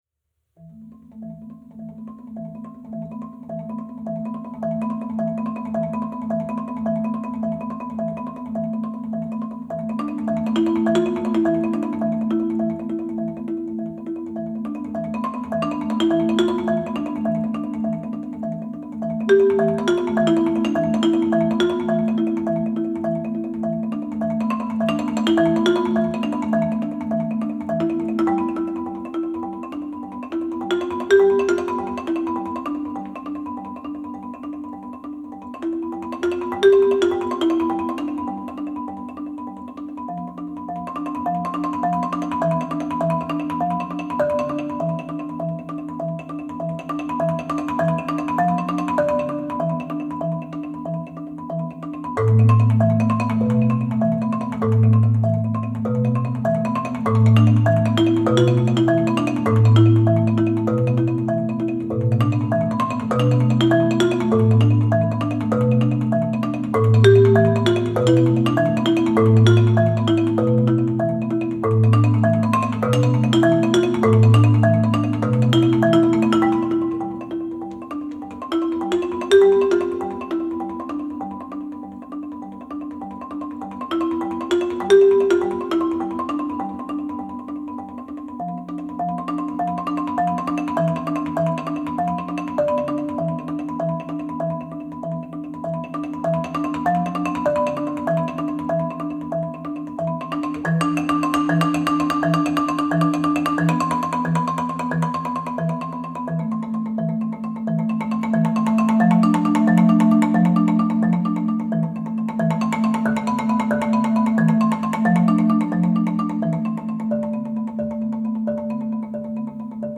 Voicing: Marimba